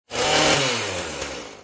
chainsaw_1s_alt.mp3